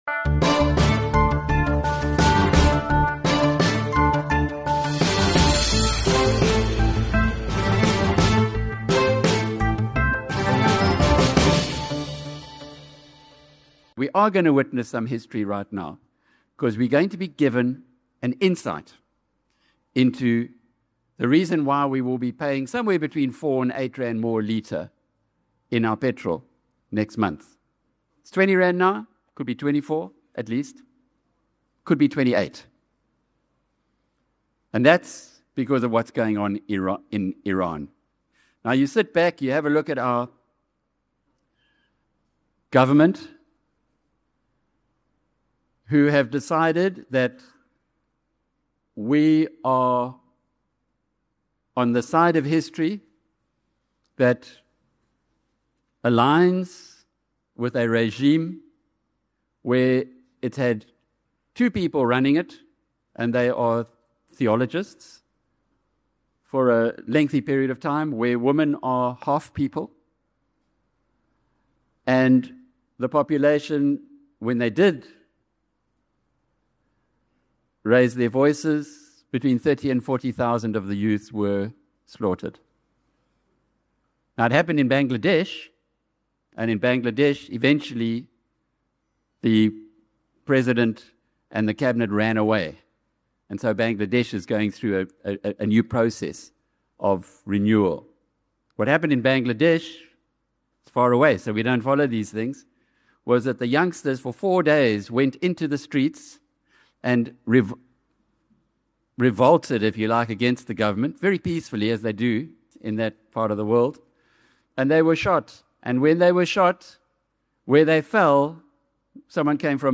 BNC#8 Keynote